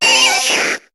Cri d'Aspicot dans Pokémon HOME.